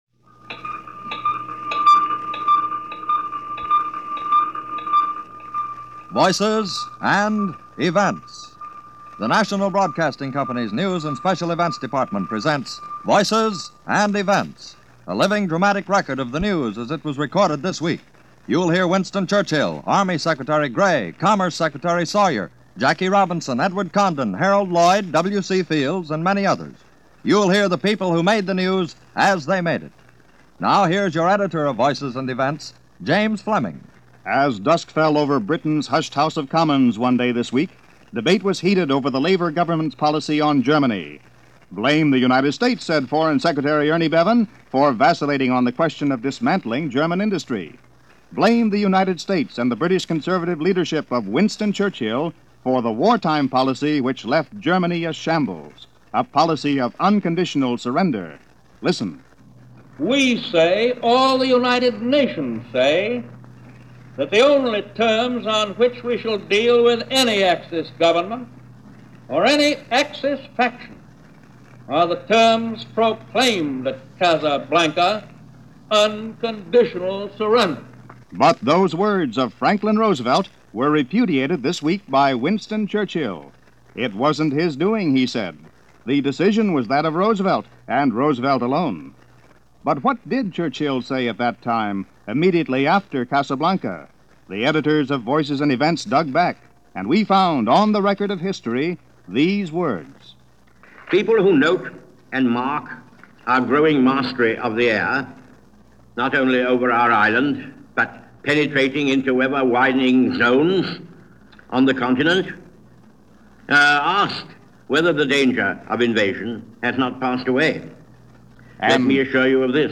The World Is Tired Of Fanaticism: Harry S. Truman - July 24, 1949 - news for the week ending July 24, 1949 from NBC Radio: Voices and Events